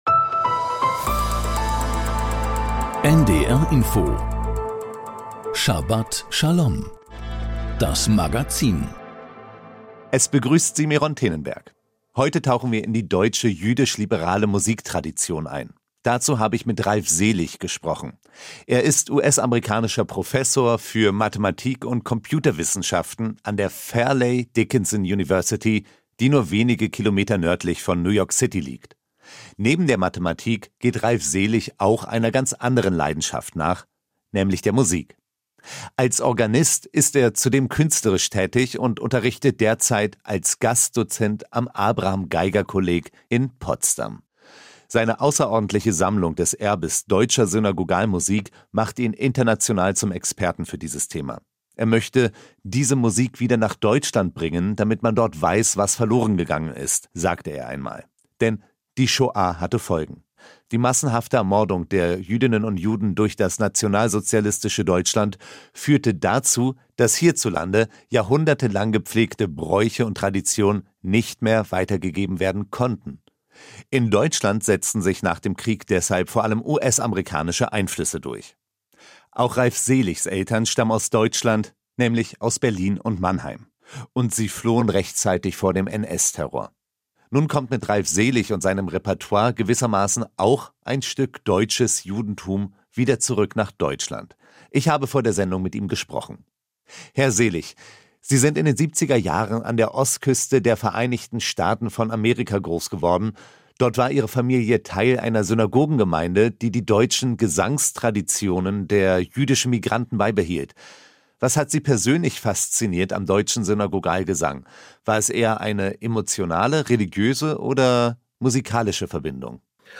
Die Themen der Sendung: Zu den Wurzeln des deutschen liberalen Chazzanuts Synagogalgesang damals und heute Interview